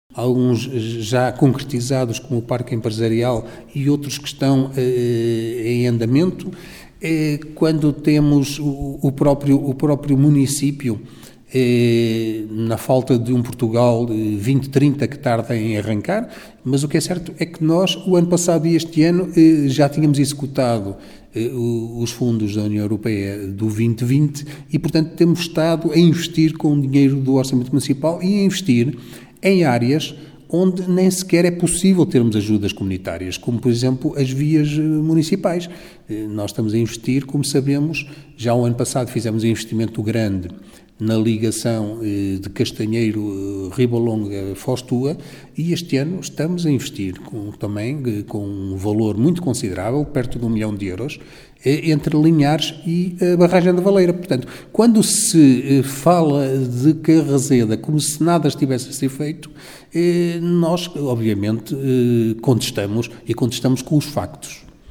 O presidente da Câmara de Carrazeda, João Gonçalves, aceita que haja discordância da oposição em relação às opções do Executivo do PSD, mas não entende que tente negar o que tem sido feito:
João Gonçalves exemplifica com projetos que considera estruturantes para o concelho: